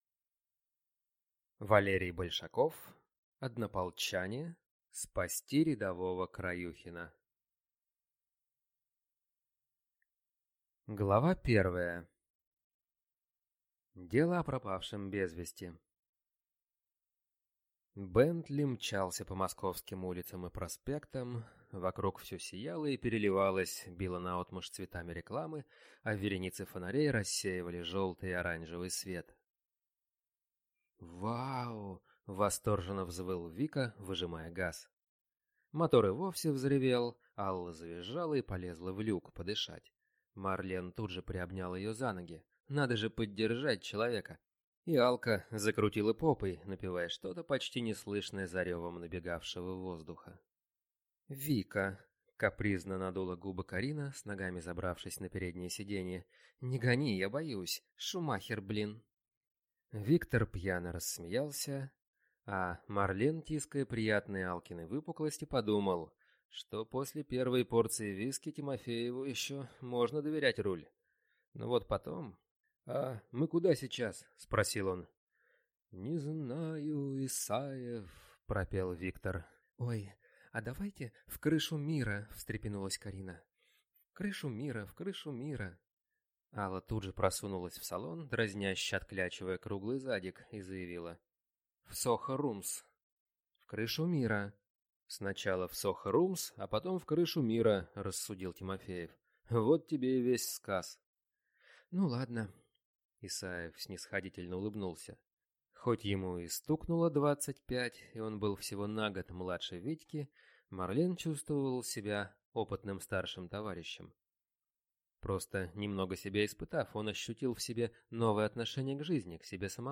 Аудиокнига Однополчане. Спасти рядового Краюхина | Библиотека аудиокниг